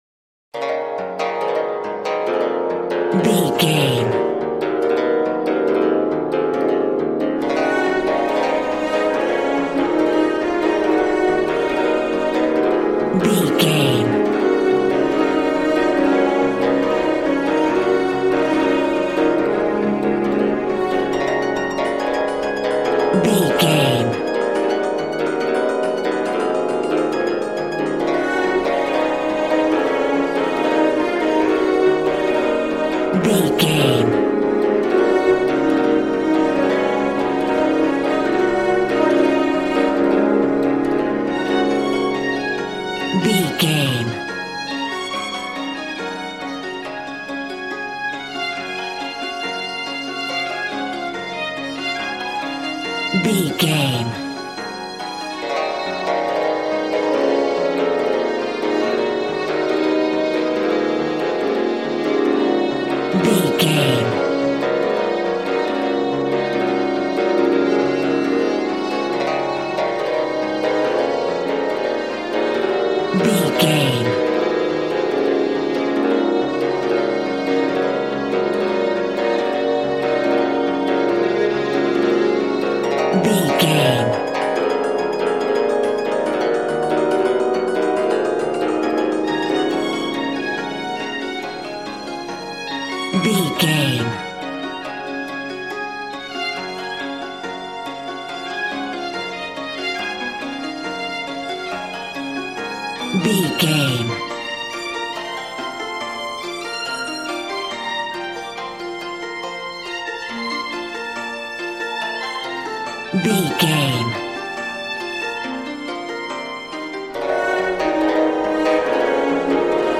Aeolian/Minor
E♭
smooth
conga
drums